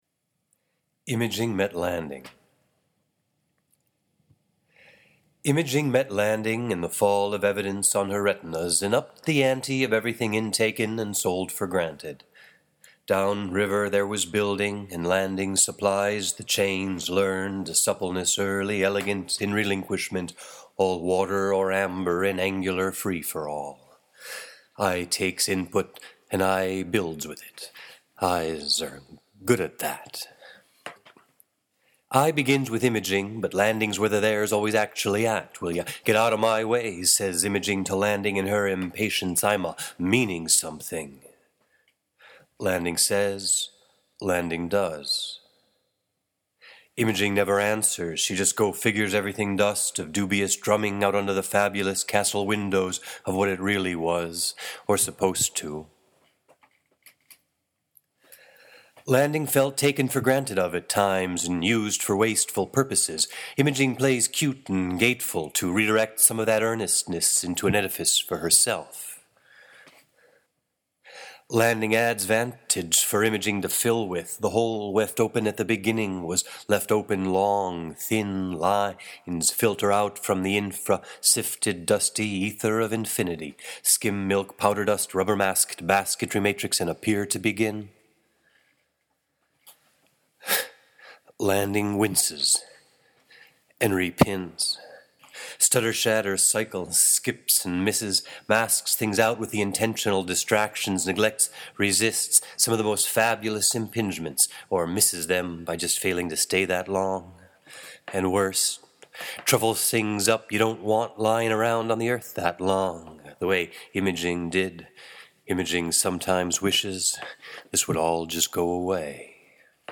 This text was generated in a single pauseless improvisation (the time of production being close to the time of reading aloud). It explores the notions of landing and imaging in the landing site theory of Arakawa and Gins.